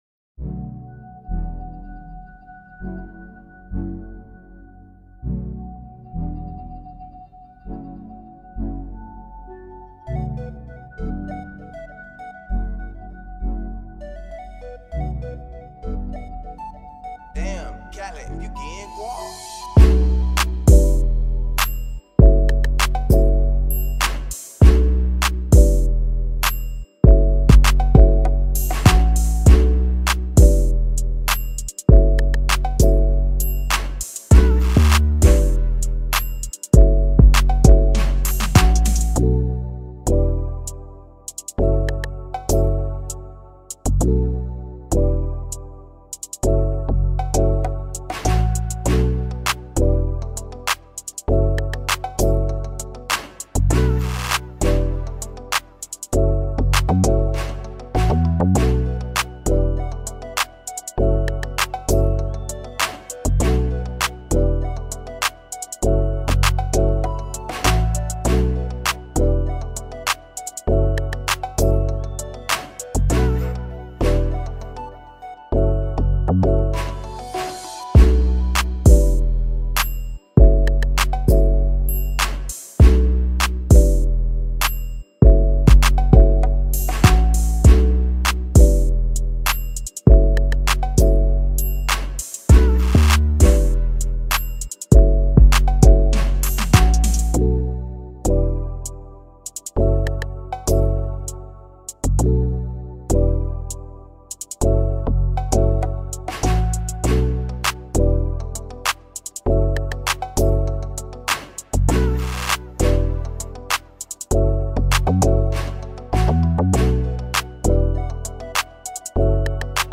2022 in Hip-Hop Instrumentals